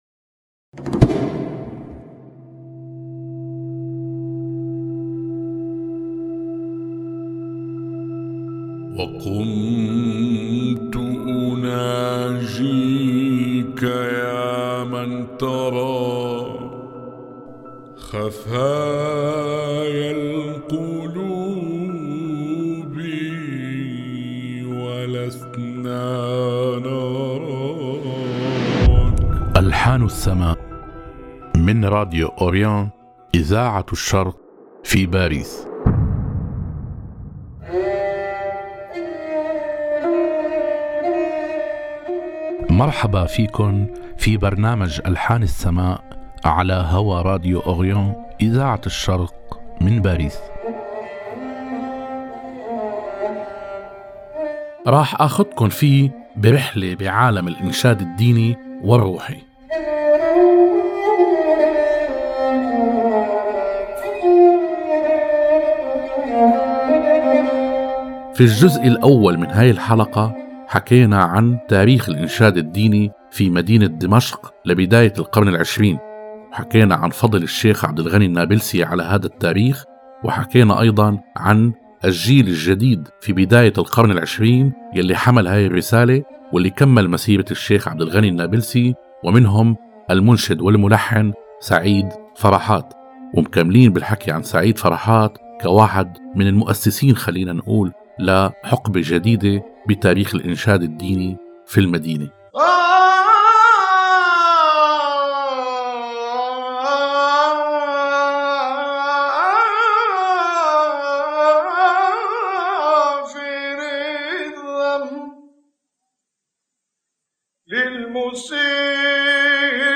صوت جميل للغاية وأداء مبهر حقيقة